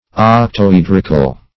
Octoedrical \Oc`to*ed"ric*al\, a.